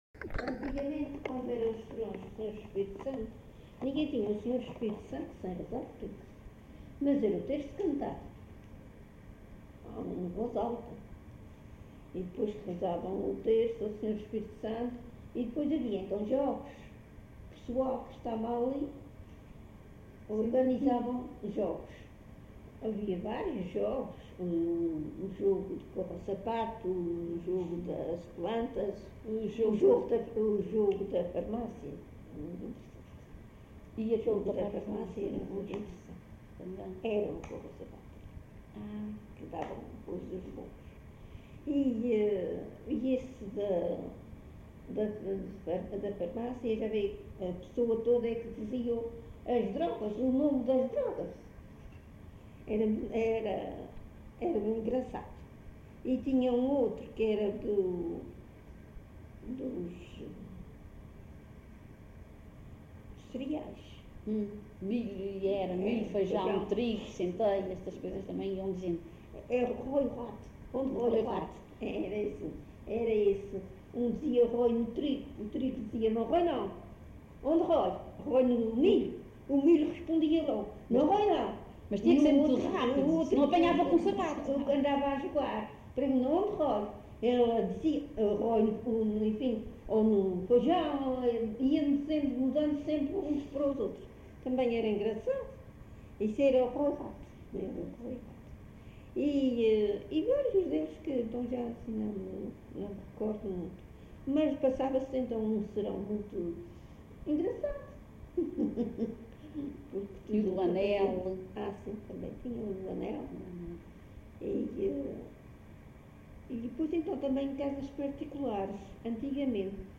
LocalidadeCarapacho (Santa Cruz da Graciosa, Angra do Heroísmo)